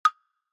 muyu.mp3